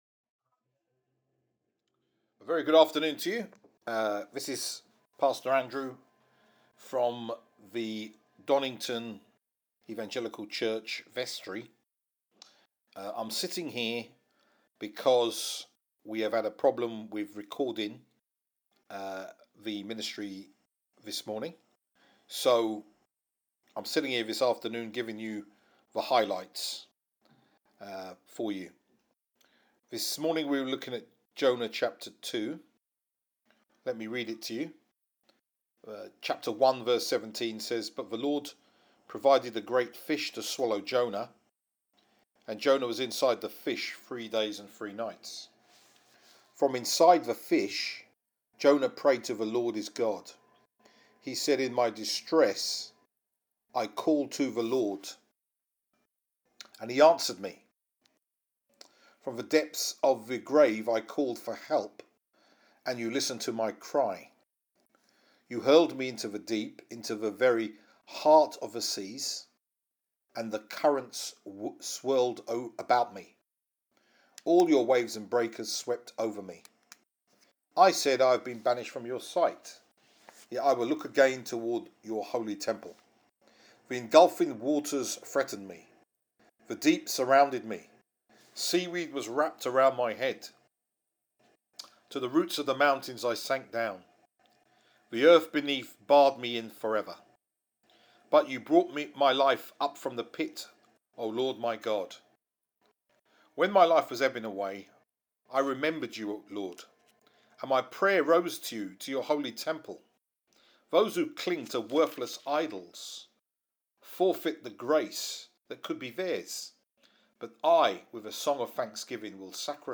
Donnington Evangelical Church